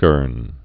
(gûrn)